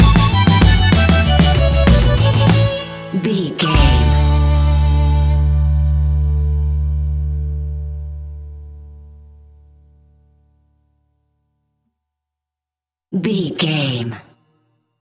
Uplifting
Ionian/Major
D
acoustic guitar
mandolin
ukulele
lapsteel
drums
double bass
accordion